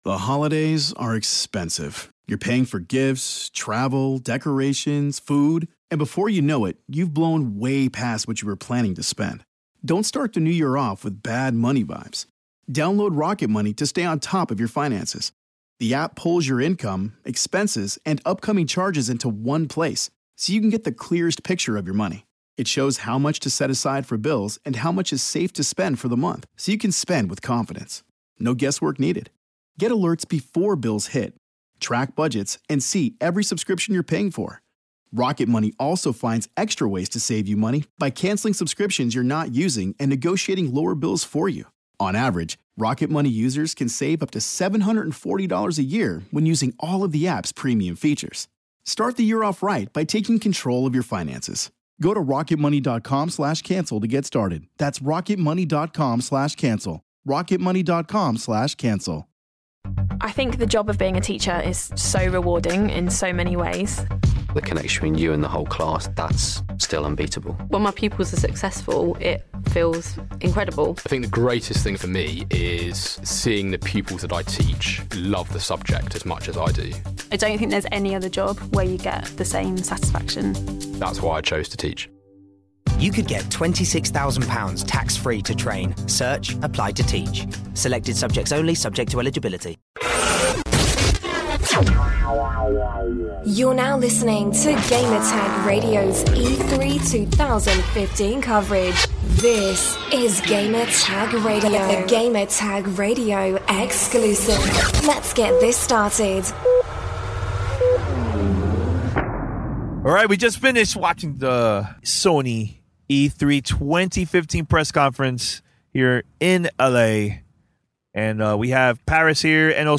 Roundtable discussion about PlayStation E3 2015 Press Conference